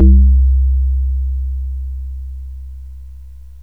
cx5 square.wav